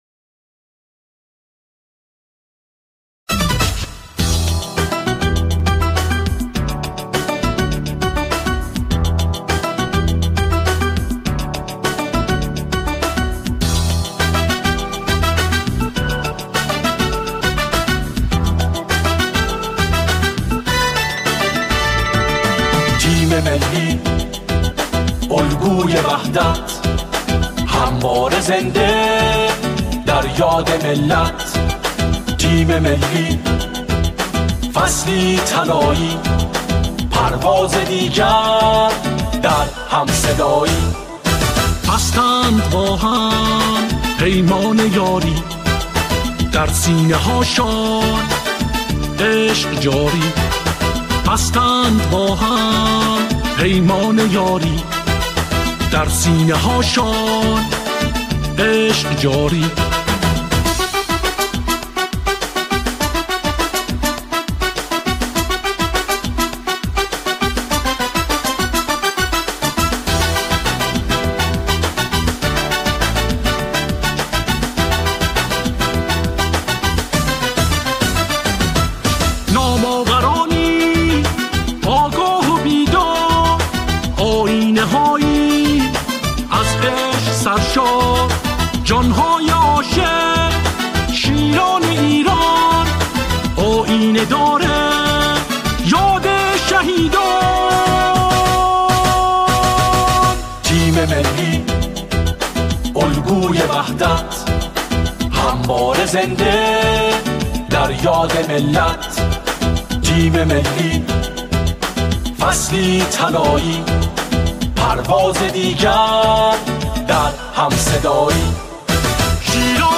سرودهای ورزشی
گروهی از همخوانان